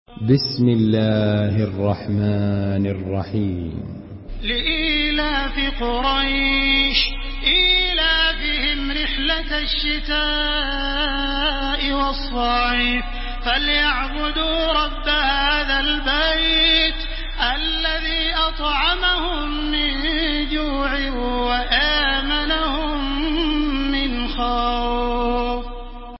Surah قريش MP3 by تراويح الحرم المكي 1430 in حفص عن عاصم narration.